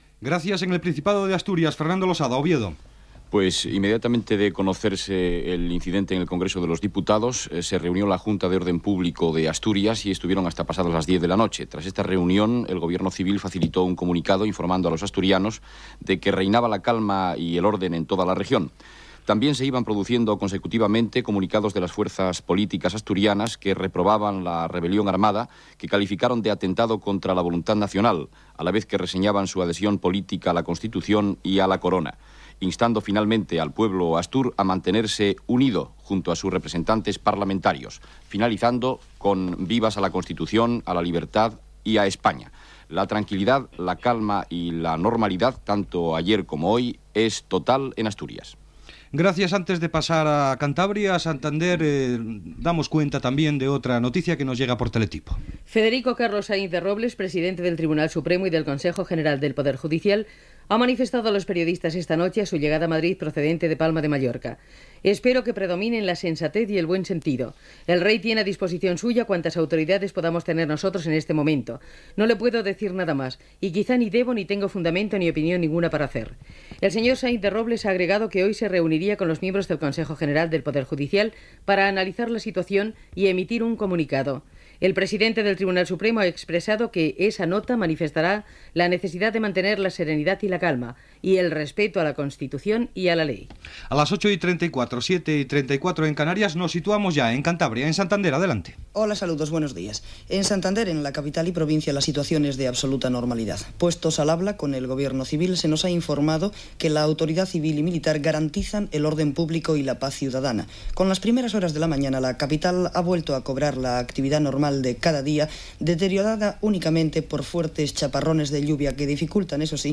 Connexió amb la unitat mòbil que està a les rodalies del Palau de les Corts, a Madrid.
Gènere radiofònic Informatiu